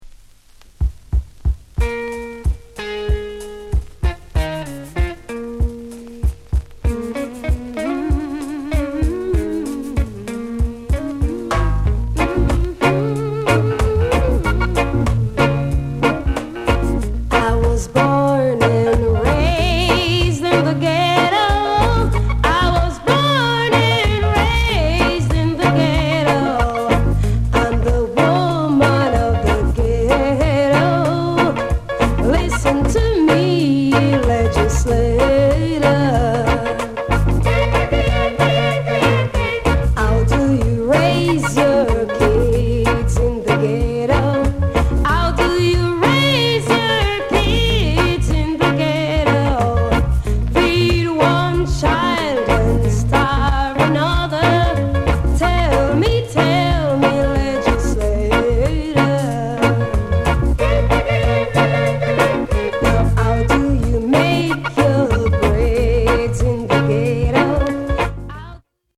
FUNKY REGGAE